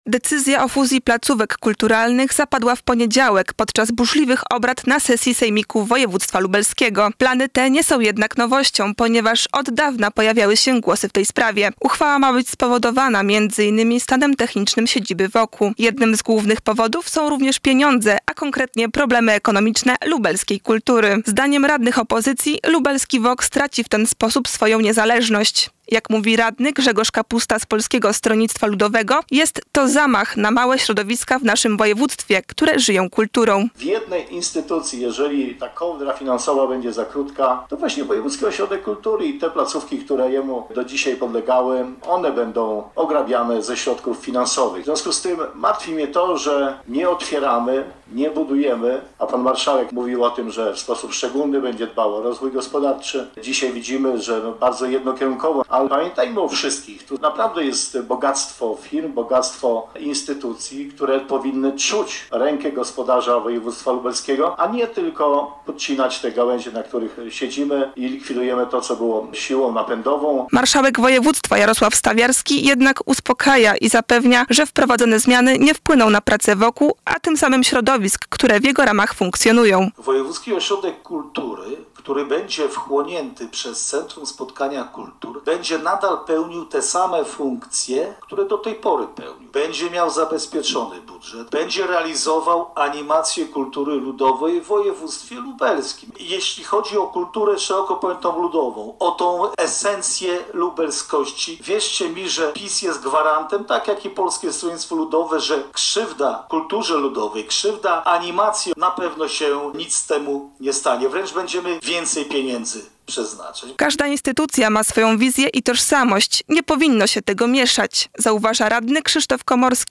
Szczegółowe informacje na ten temat przygotowała nasza reporterka